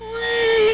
Monster.mp3